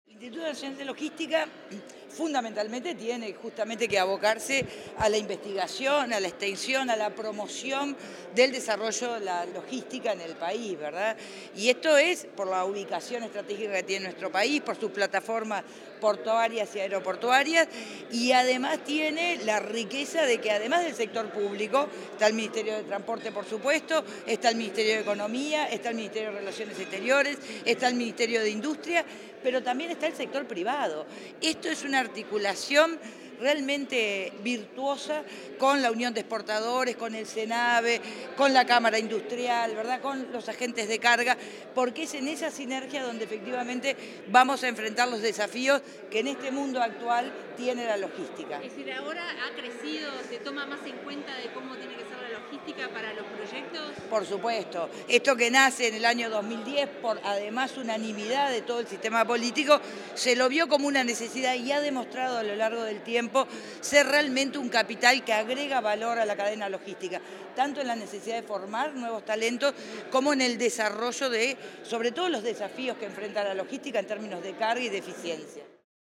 Declaraciones de la ministra de Transporte, Lucía Etcheverry
La ministra de Transporte y Obras Públicas, Lucía Etcheverry, dialogó con los medios de prensa tras la ceremonia conmemorativa del 15.° aniversario